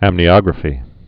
(ămnē-ŏgrə-fē)